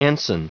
Prononciation du mot ensign en anglais (fichier audio)
Prononciation du mot : ensign